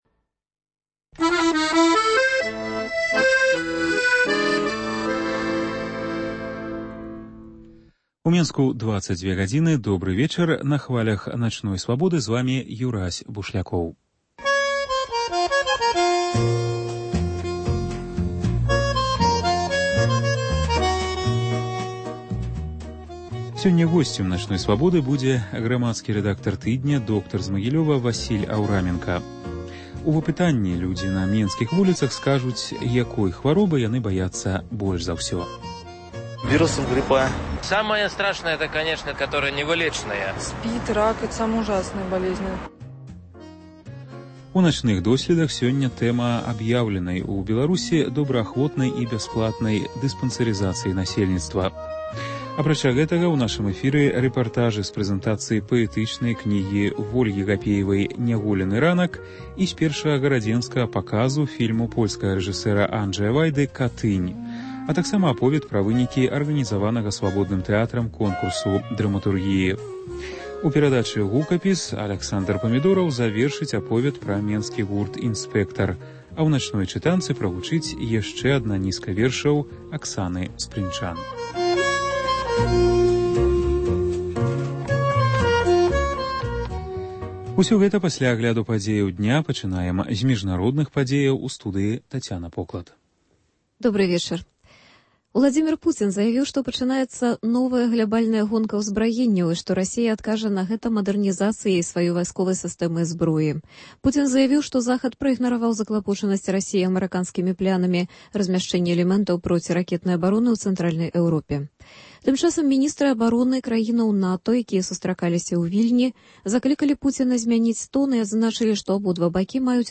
Апытаньне на менскіх вуліцах: якой хваробы вы баіцеся больш за ўсё? “Начны досьлед” -- абавязковыя мэдычныя агляды.